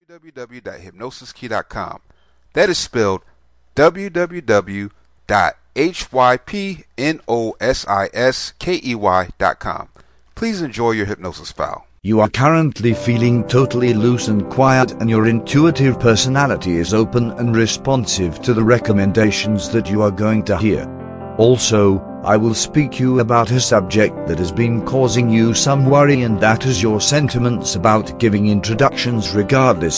Presentations Self Hypnosis Mp3